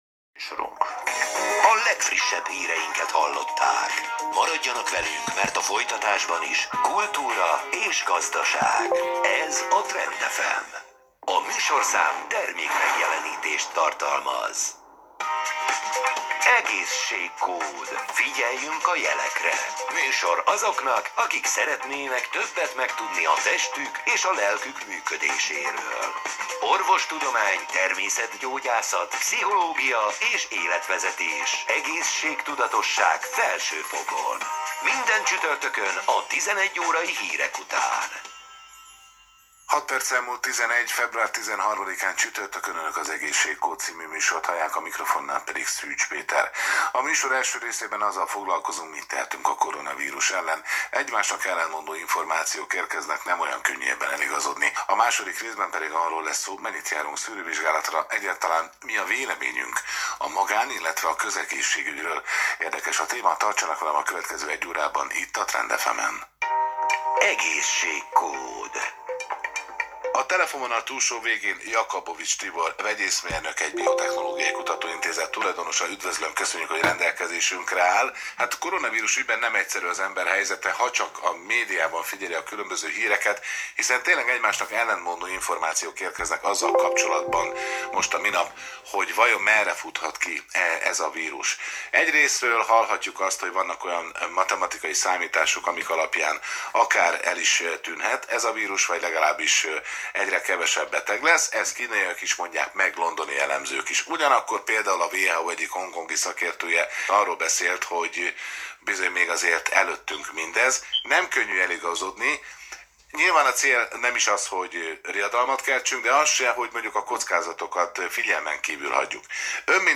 koronavirus_interju.m4a